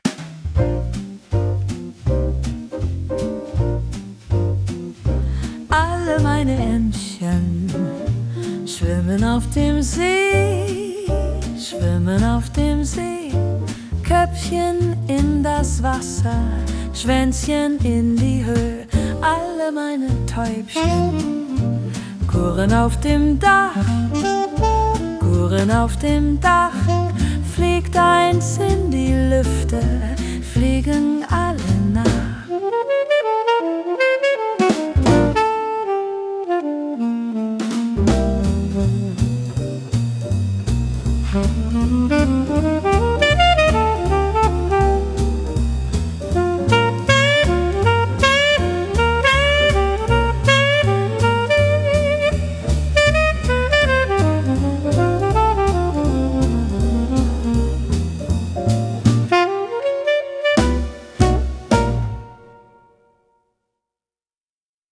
Alle meine Entchen – Jazz [Jazz, smooth saxophone, upright bass, swing rhythm, relaxed female vocals, sophisticated]